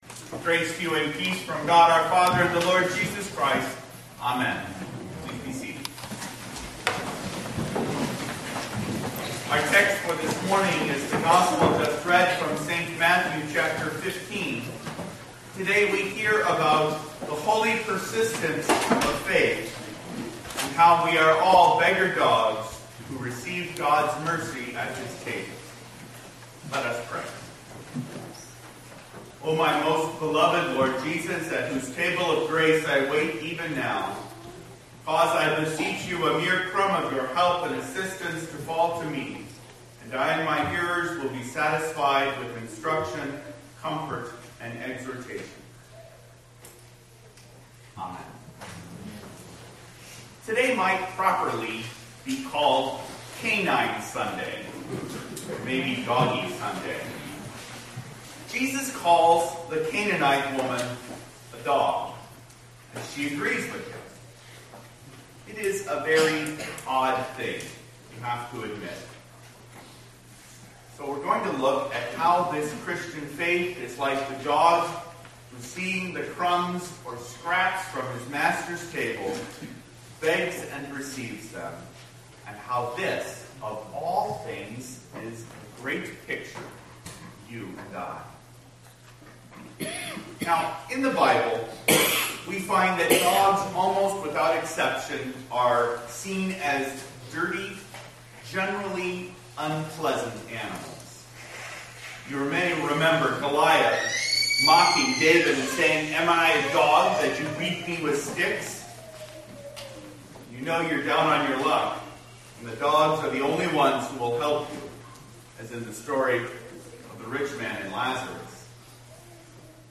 Reminiscere Sunday 2012Â (Portions received with thanks from Johann Heermann)